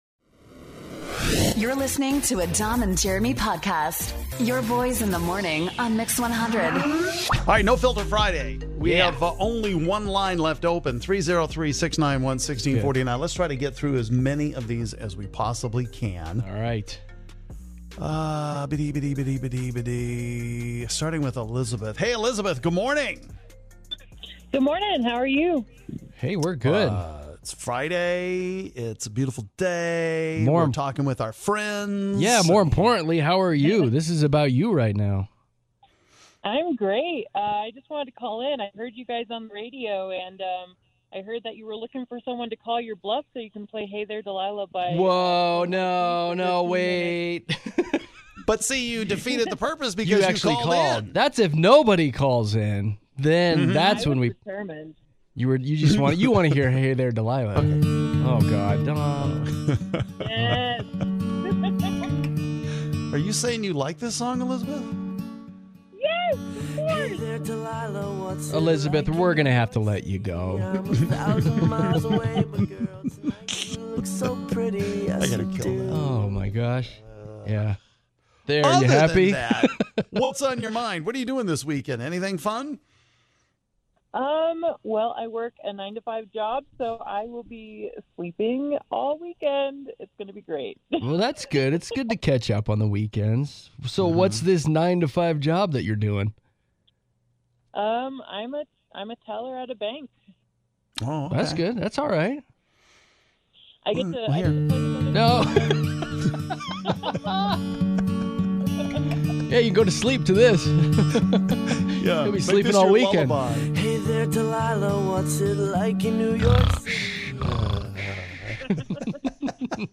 We talk to YOU, our listeners, about whatever is on your mind!